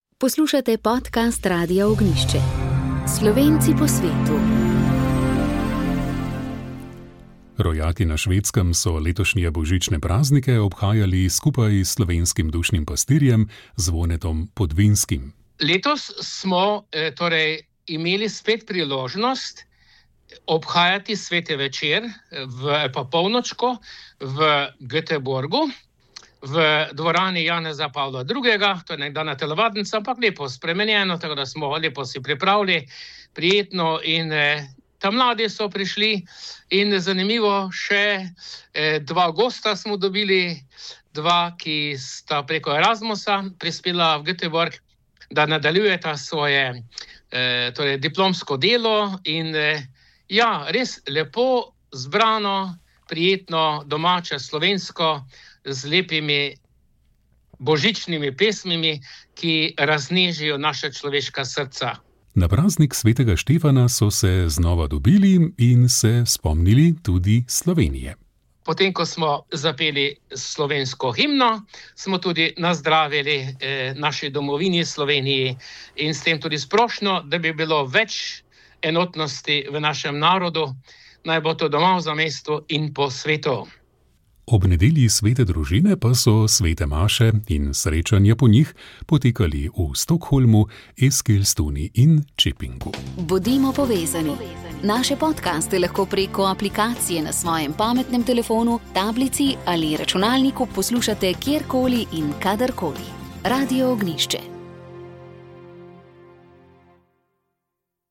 Pogovor z mariborskim nadškofom Alojzijem Cviklom